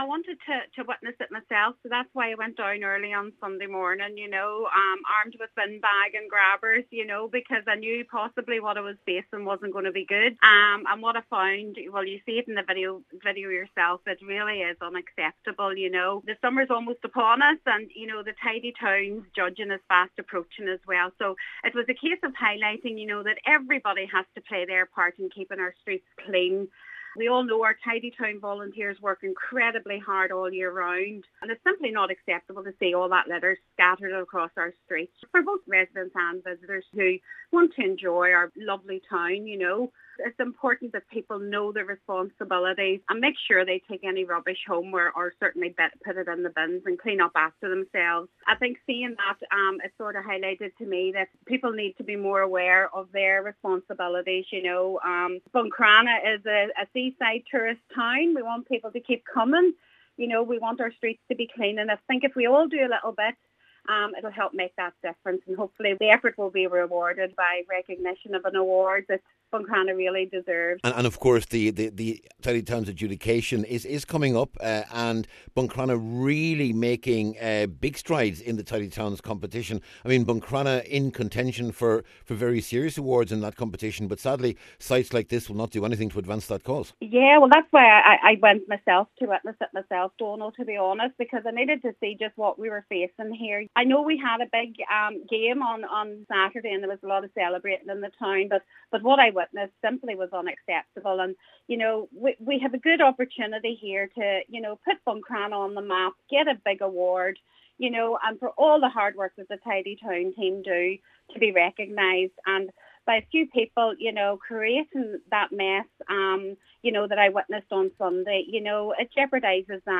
A Buncrana Councillor says it is everyone’s responsibility to take their rubbish home.